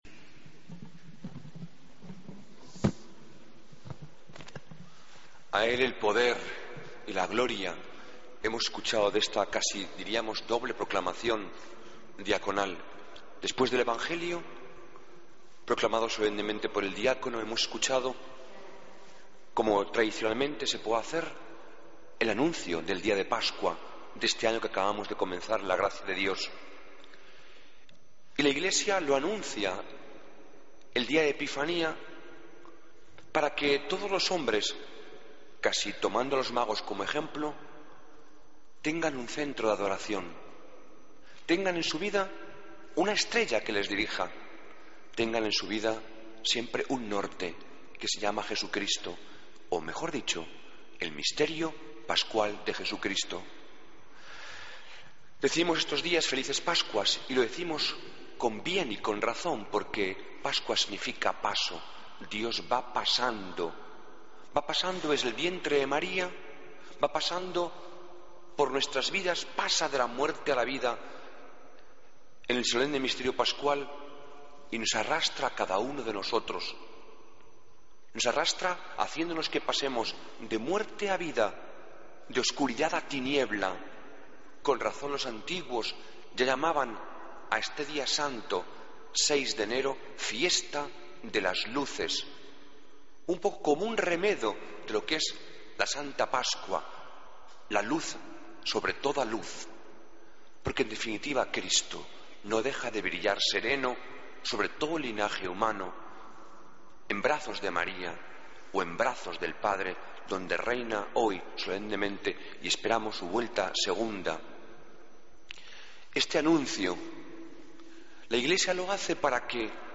Homilía del domingo 6 de enero de 2013